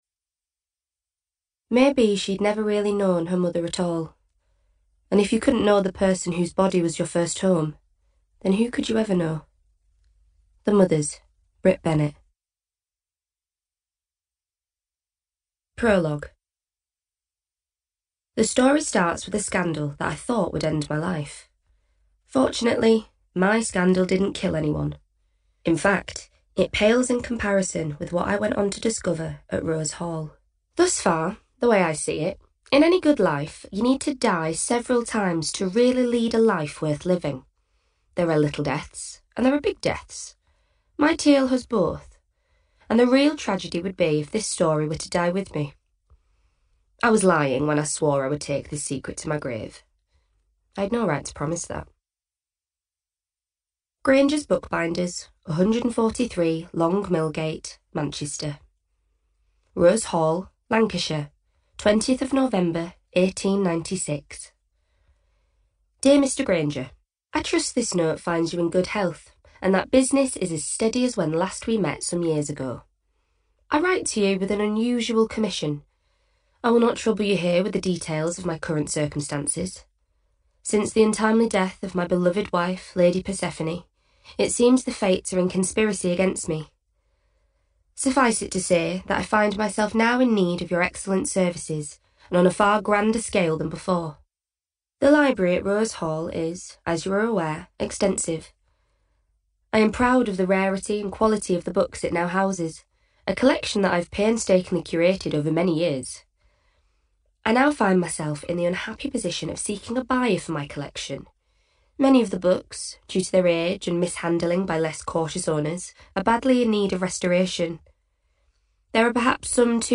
Mancunian
Northern
Female
Conversational
Soft
THE LIBRARY THIEF AUDIOBOOK